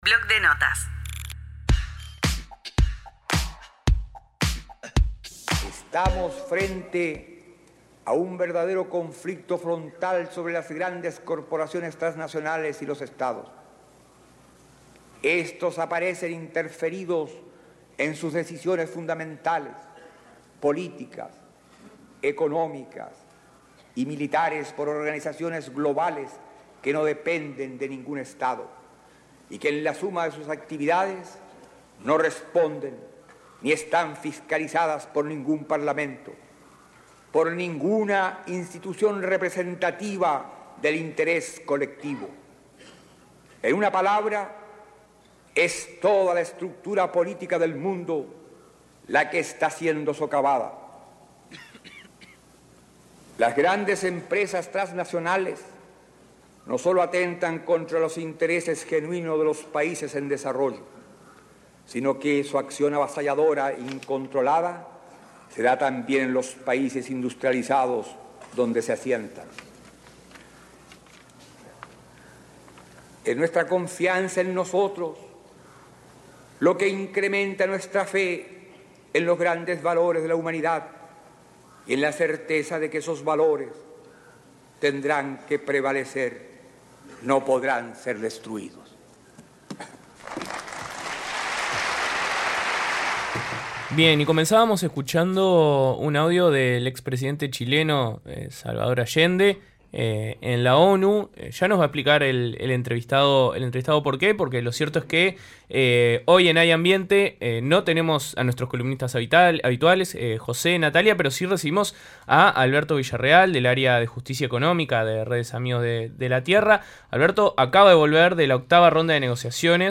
Comunidad Udelar, el periodístico de UNI Radio. Noticias, periodismo e investigación siempre desde una perspectiva universitaria.